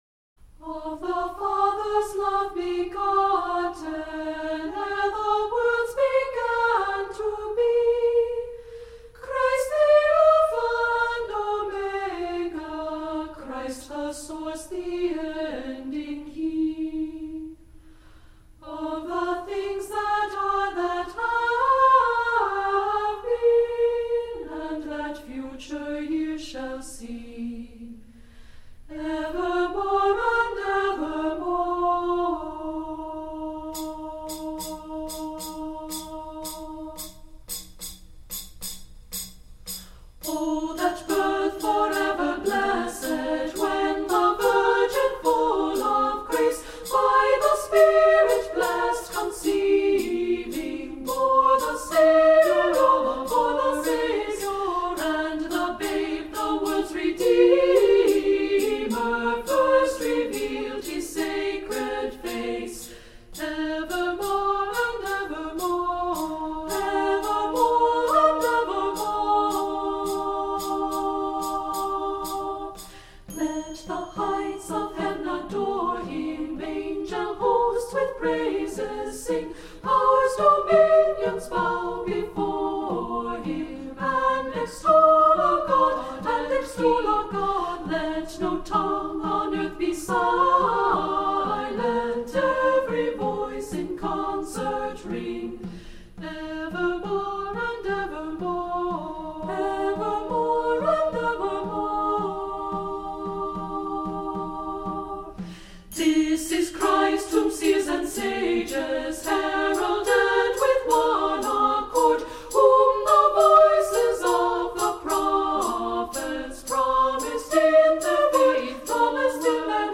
Voicing: "Two-part Equal Voices or Children's Choir"